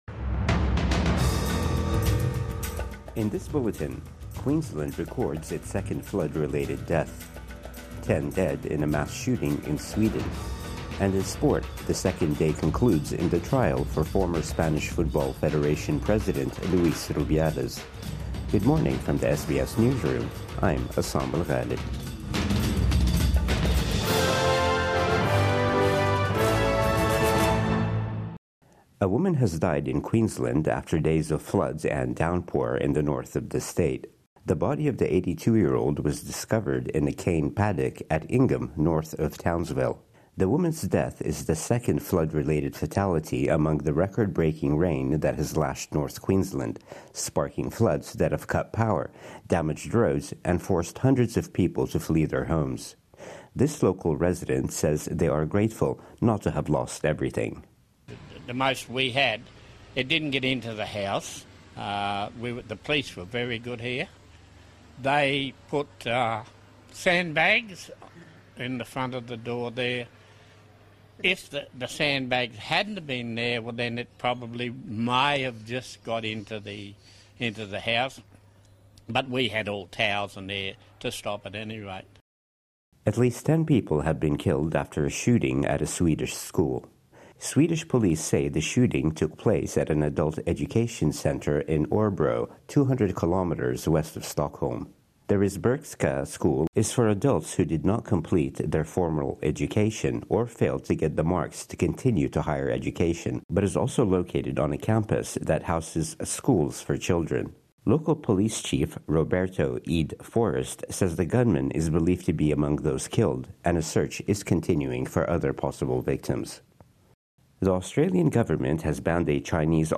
Morning News Bulletin 5 February 2025